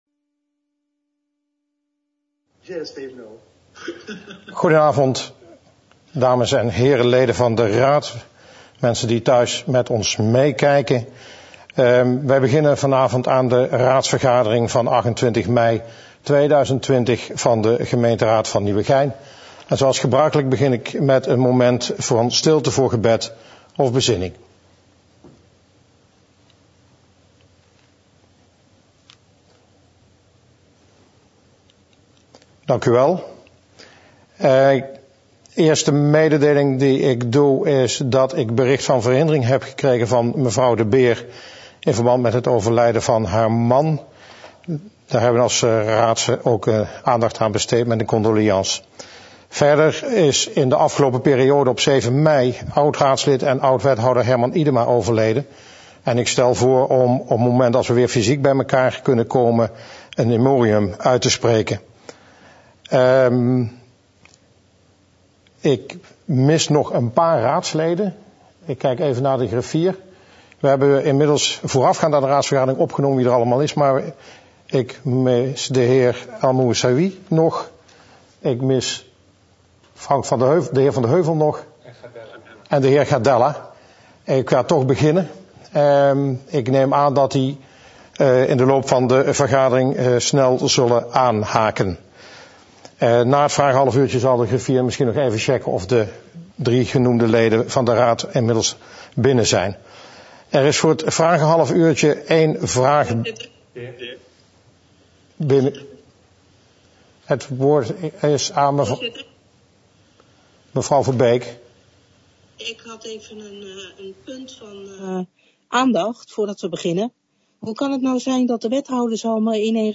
Agenda Nieuwegein - Raadsvergadering donderdag 28 mei 2020 20:00 - 23:00 - iBabs Publieksportaal
Online, te volgen via livestreaming